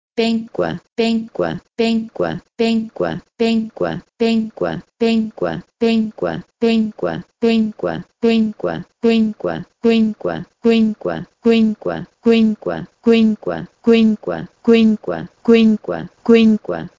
PIE-penkwe-to-Latin-quinque.mp3